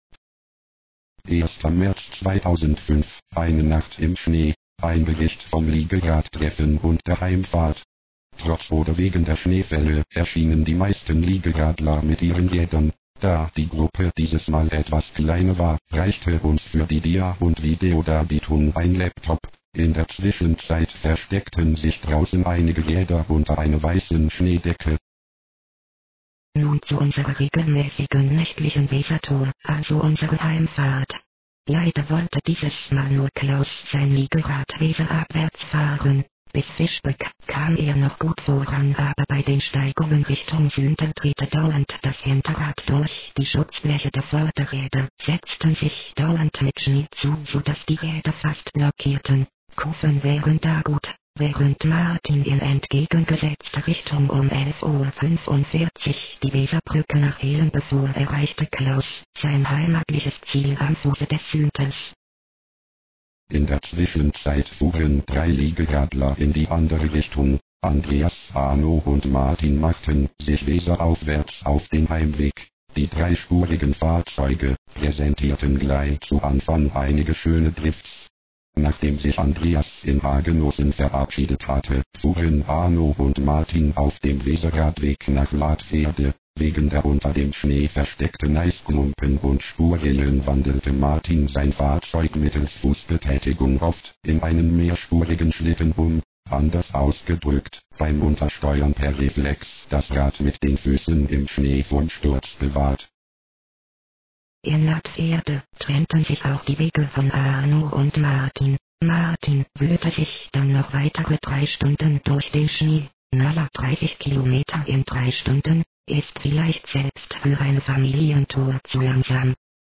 Zu diesem Bericht gibt's auch eine gesprochene Version als Audiodatei: